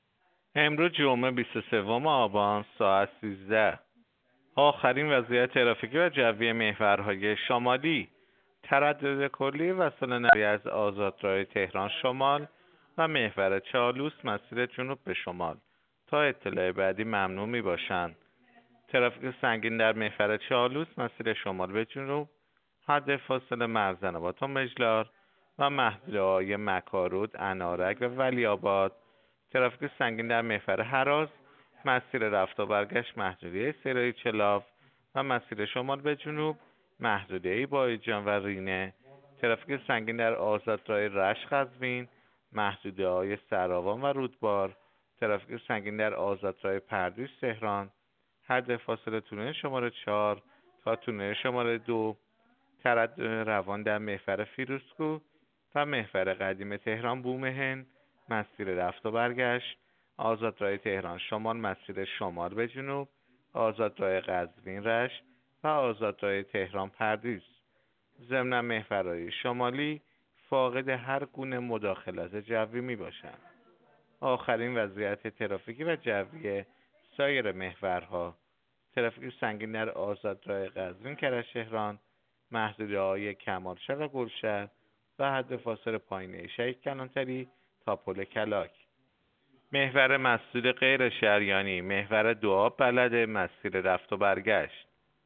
گزارش رادیو اینترنتی از آخرین وضعیت ترافیکی جاده‌ها ساعت ۱۳ بیست و سوم آبان؛